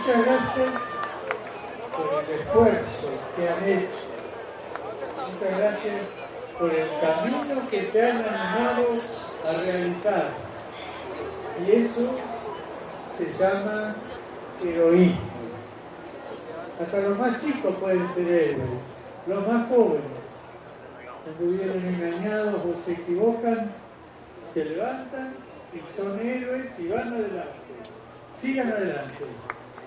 Las primeras palabras del Papa Francisco a su llegada a Bogotá
Así pudo llegar al caer la noche a la Nunciatura, situada en el tradicional barrio de Teusaquillo, donde en sus primeras palabras en suelo colombiano, pronunciadas desde una pequeña tarima, les agradeció por "la valentía" y "el coraje" y les pidió que "no se dejen robar la alegría (...) y la esperanza".